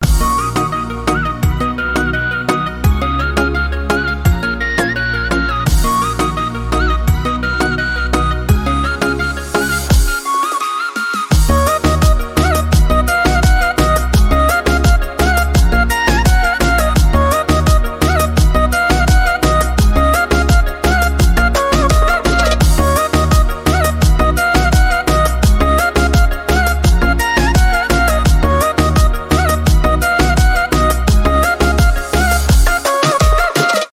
танцевальные
восточные , без слов